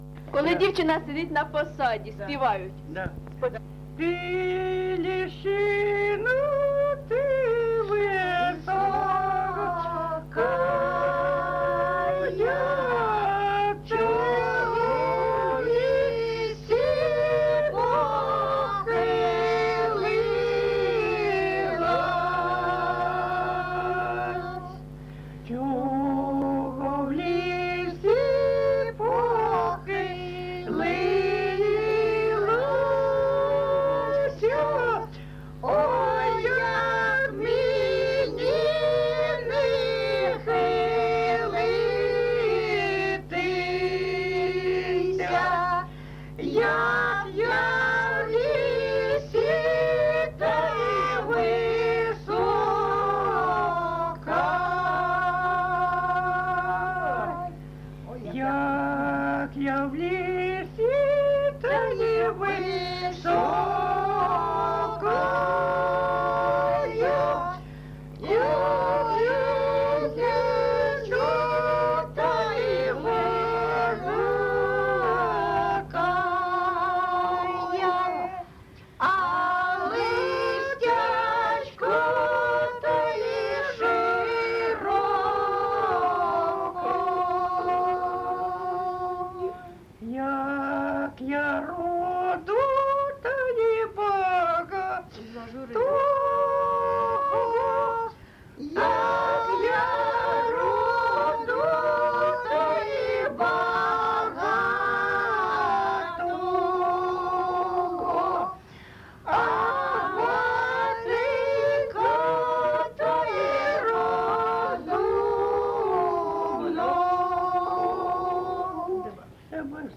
ЖанрВесільні
Місце записус. Новомиколаївка Шевченківський район, Харківська обл., Україна, Слобожанщина